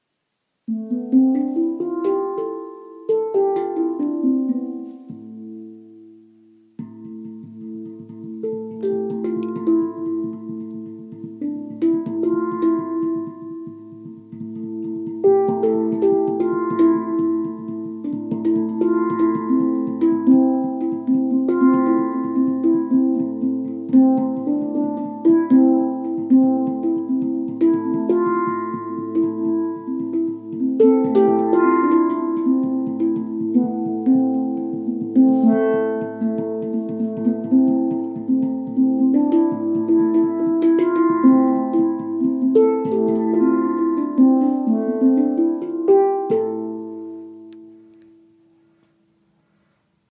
Deep, somberly minor character in A-minor
Profound character, which is well suited for rhythmic playing.
A2 A3 H3 C4 D4 E4 F4 G4 A4
A-Moll Heptatonic
Total 9 tones (8+1)
This is our creation of the “classic” Handpan.